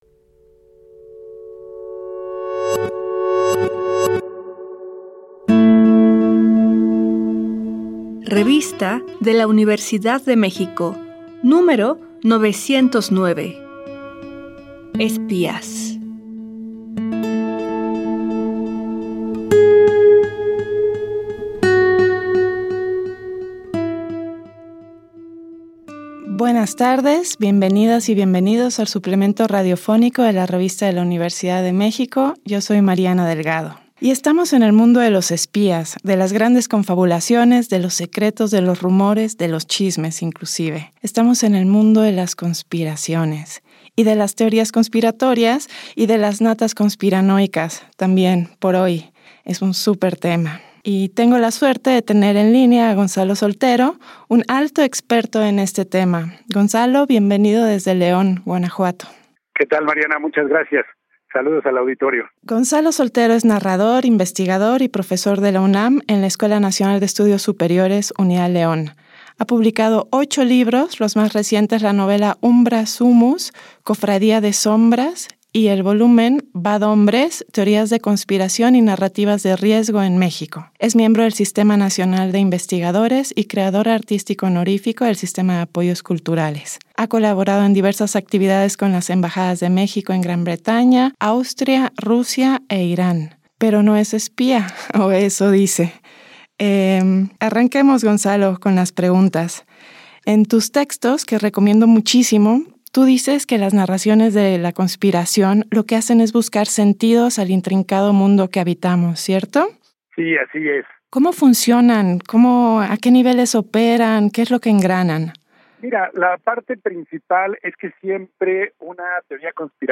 Fue transmitido el jueves 20 de junio de 2024 por el 96.1 FM.